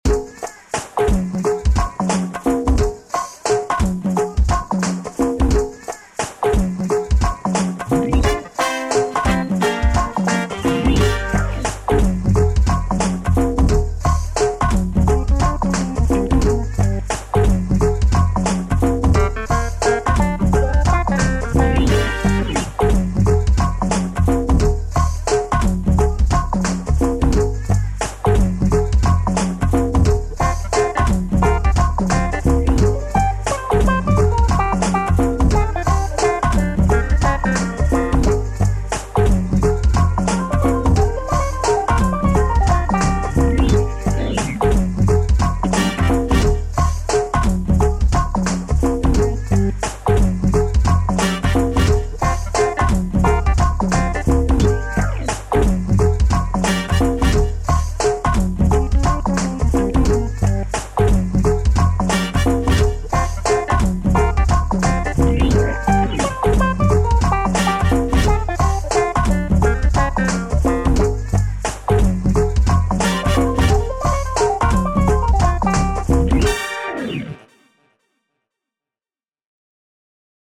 DC Go-Go, 90s, Hip Hop, Dance
Gmin